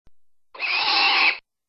bird_die.mp3